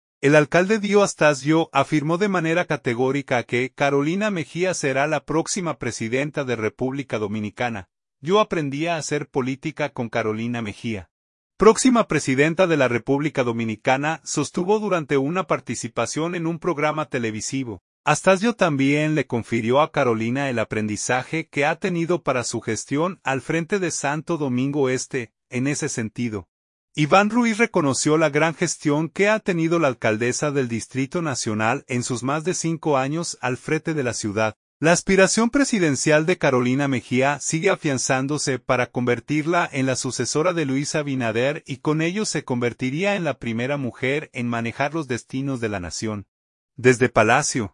“Yo aprendí a hacer política con Carolina Mejía, próxima presidenta de la República Dominicana”, sostuvo durante una participación en un programa televisivo.